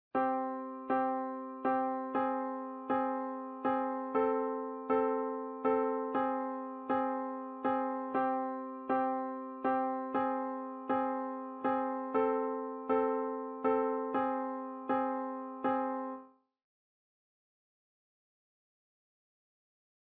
Our augmented colors are very character, one of a kind sounds.
Makes for a solid intro yes? Sort of 'secret agent man' styled from wayback or a lovely Latin romp.